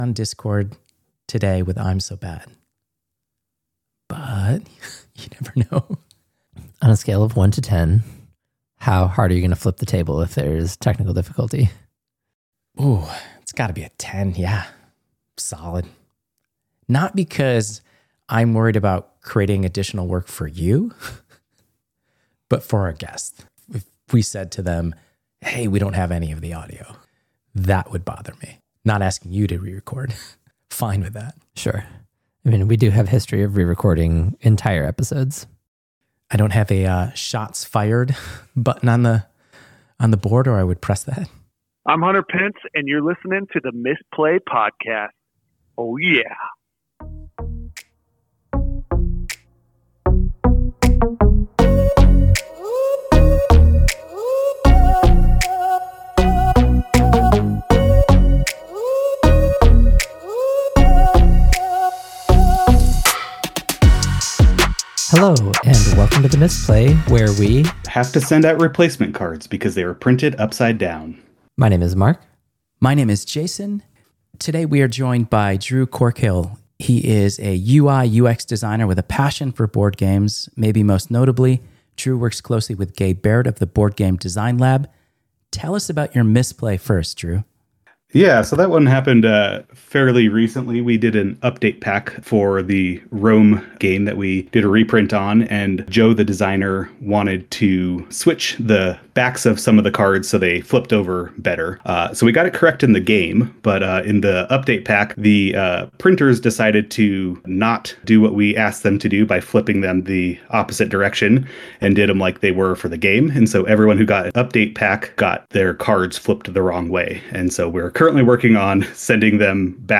we interview